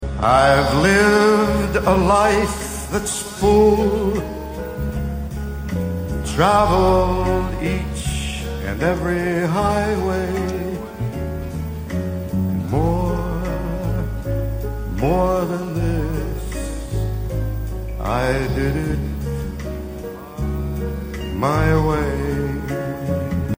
Live from Madison Square Garden in 1974.